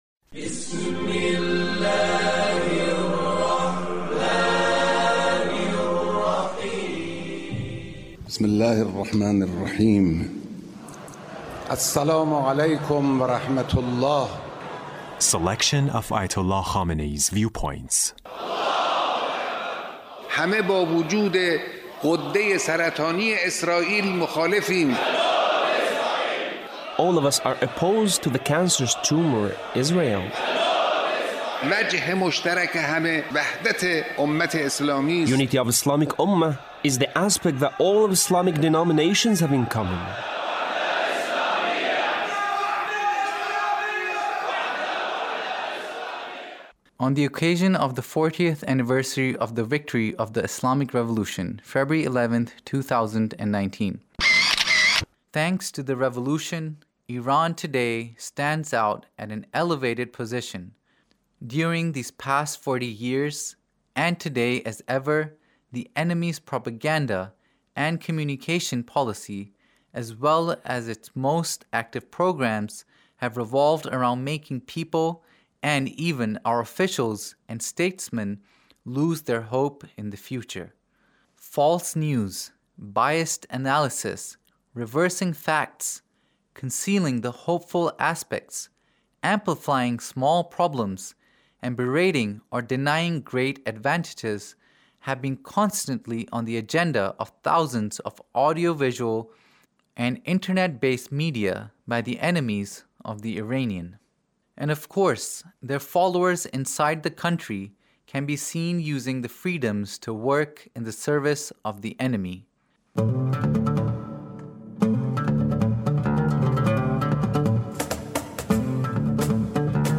Leader's Speech (1886)